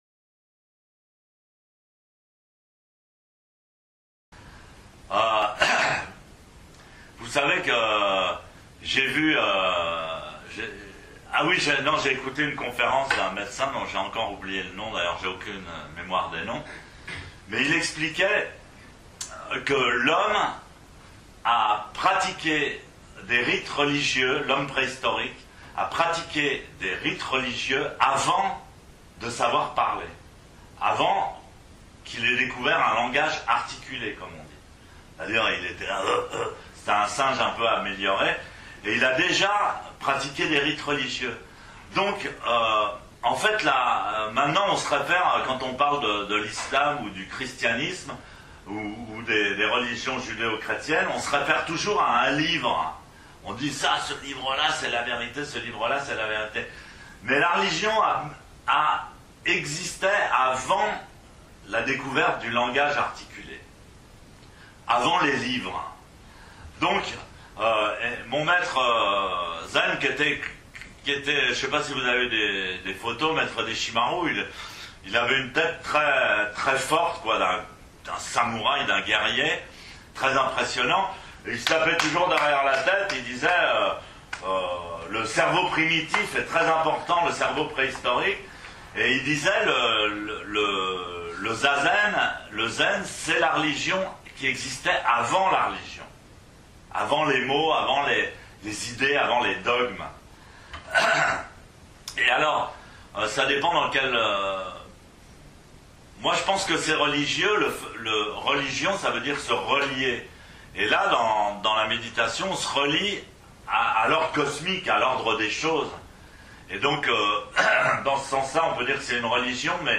Mondo - questions à un maître zen